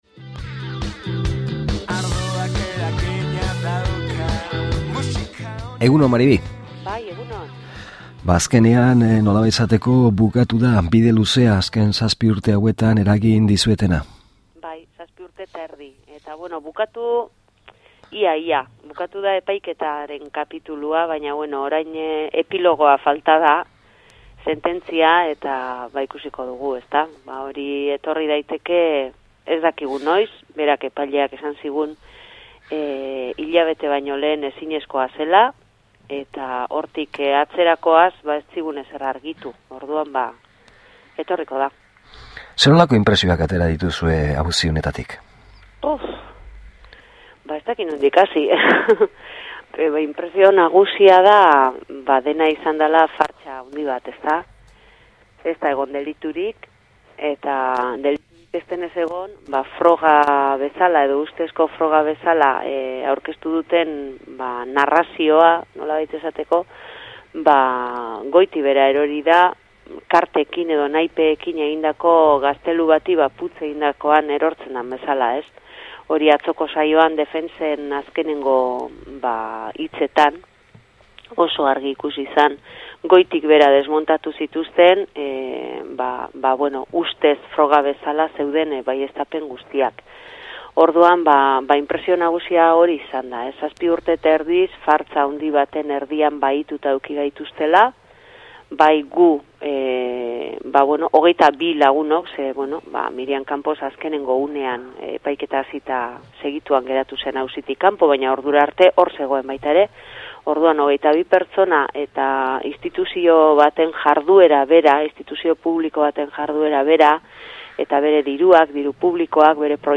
solasaldia
Elkarrizketa bukatu ondoren pasa den igandean Arrasaten Udalbiltzaren alde burututako bertso saioaren Amets Arzalluz eta Unai Iturriagaren bertso batzuk entzungai dira.